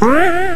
a trampoline1.ogg